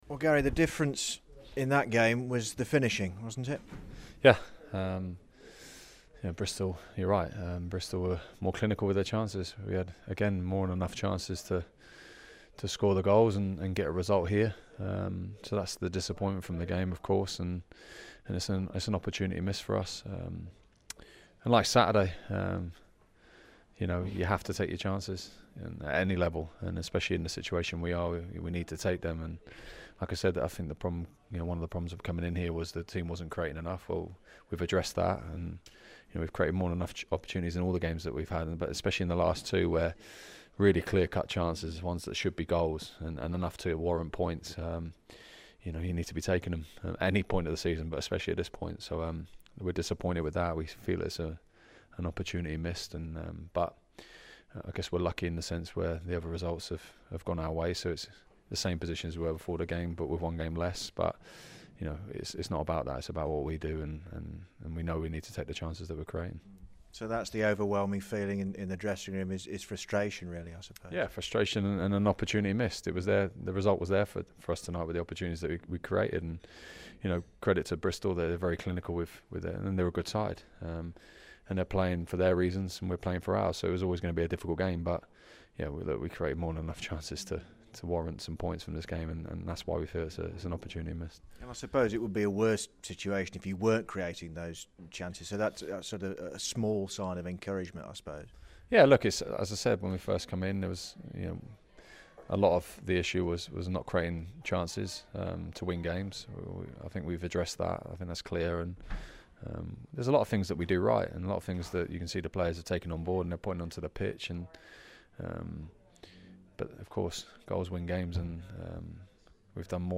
talks to Garry Monk after Blues' 3-1 defeat to Bristol City at Ashton Gate.